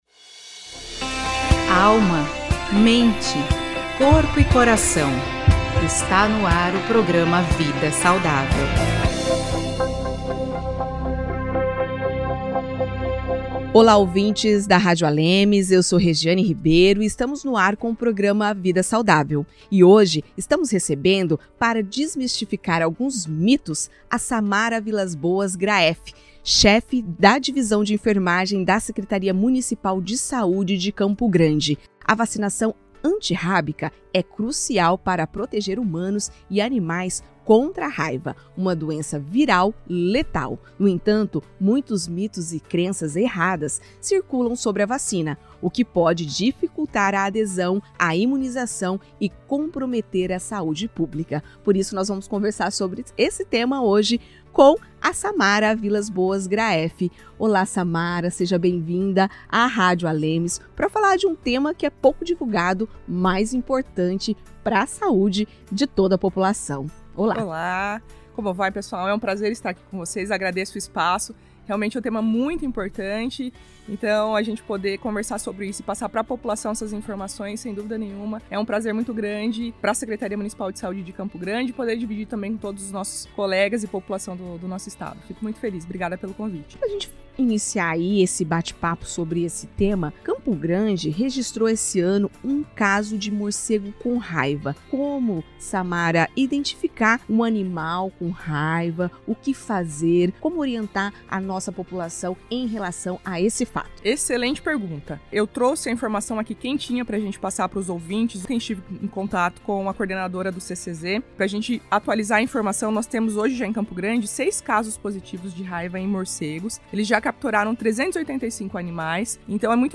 Durante entrevista ao programa Vida Saudável, da Rádio ALEMS